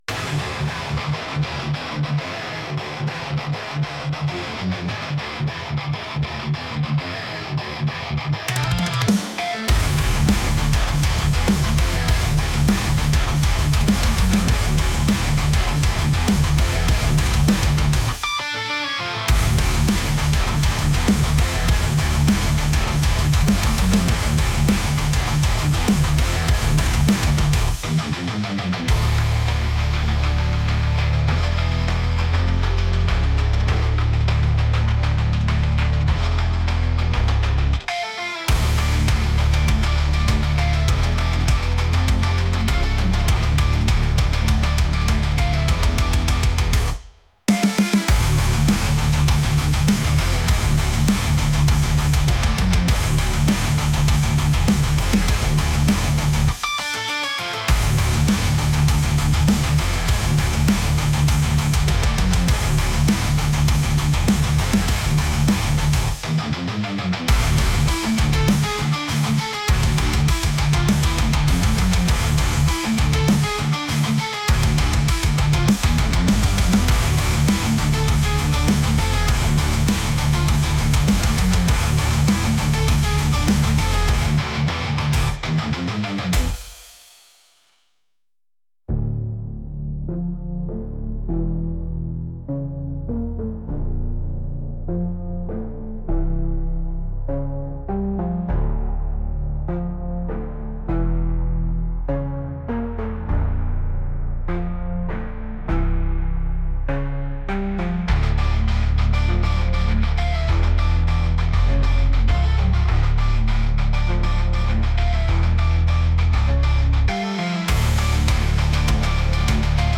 ギターを使ったメタルチックでゲームのボス戦のような曲です。